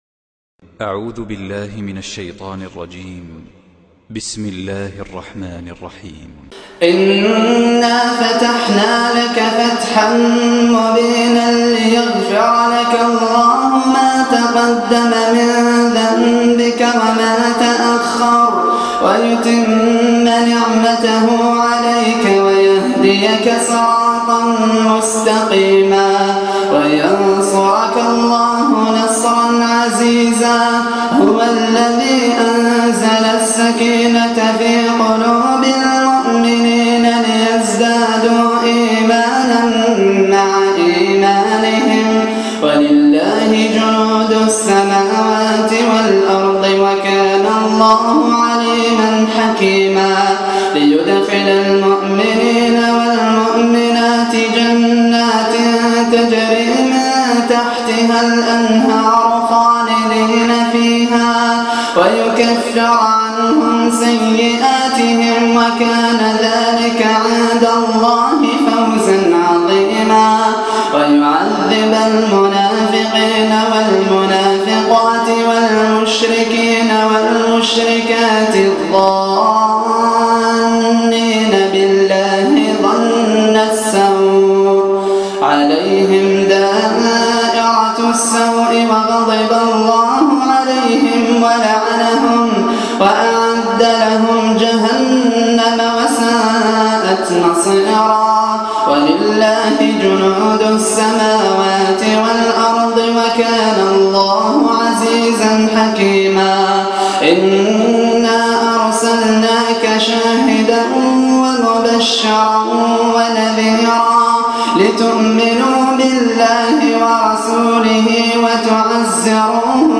تلاوة عطرة بصوت هادئ سورة الفتح كاملة
صوت جميل وخاشع